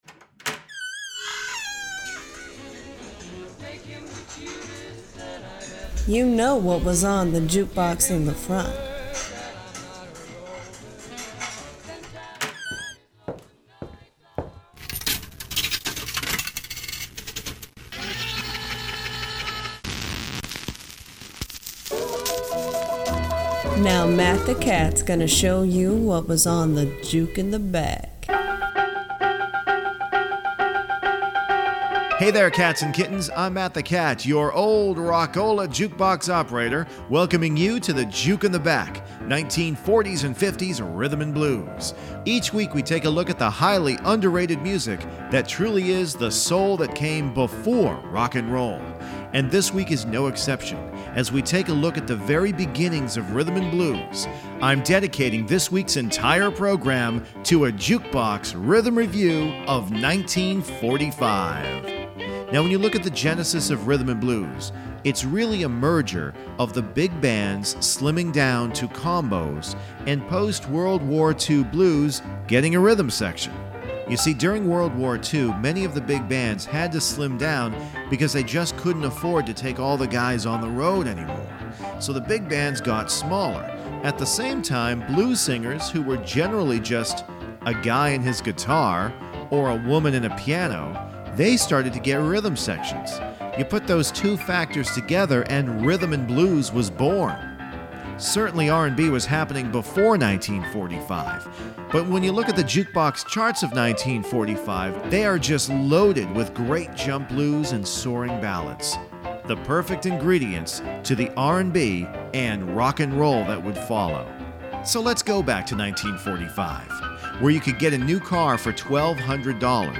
This was a pivotal year for Rhythm and Blues Music as 1945 marked the beginning of a new sound in Black Music.